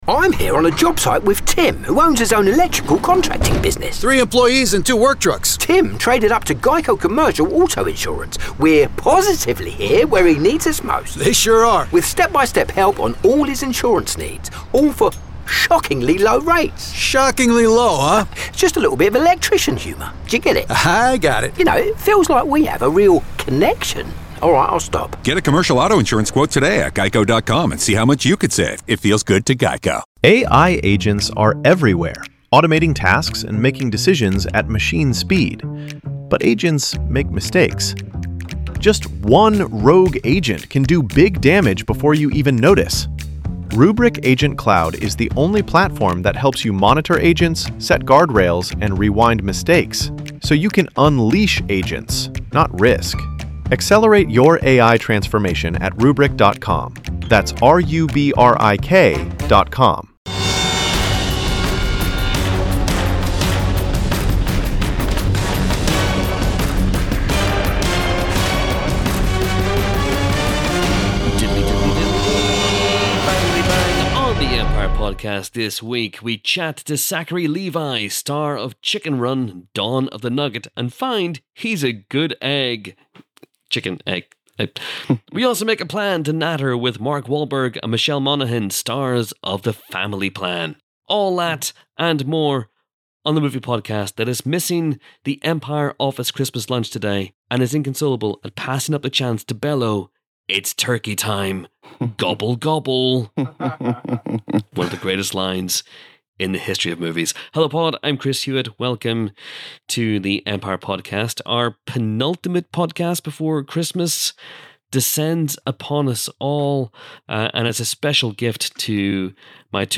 Film Reviews